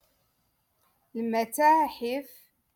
Moroccan Dialect - Rotation Two- Lesson Forty Eight